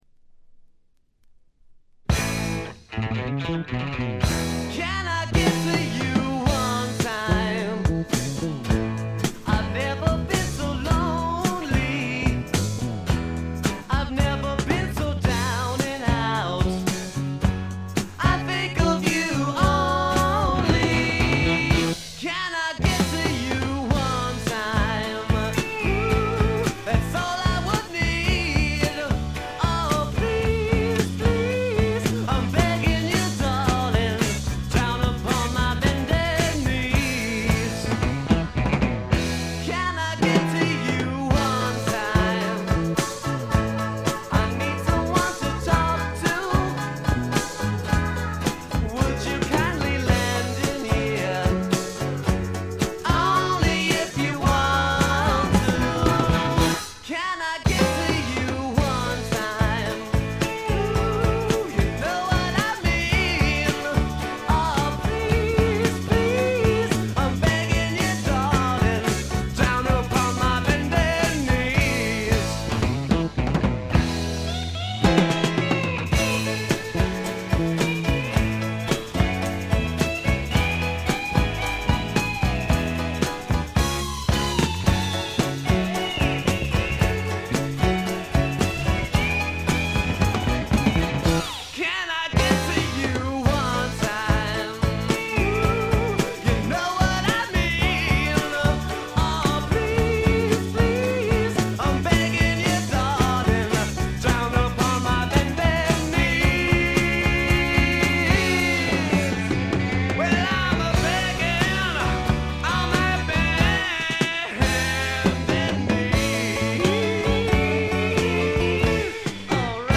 ほとんどノイズ感無し。
パワー・ポップ、ニッチ・ポップ好きにもばっちりはまる傑作です。
試聴曲は現品からの取り込み音源です。
Lead Vocals, Guitar, Piano